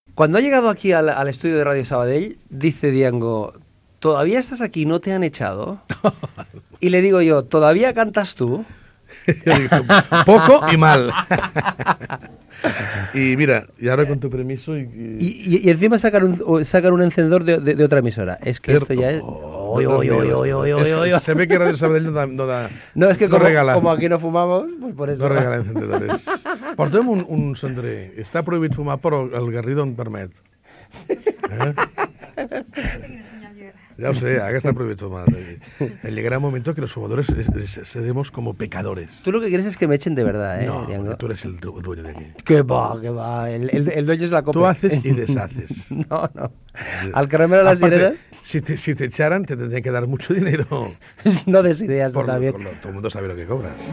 Inici de l'entrevista al cantant Dyango (Josep Gómez Romero ), tot parlant del tabac
Entreteniment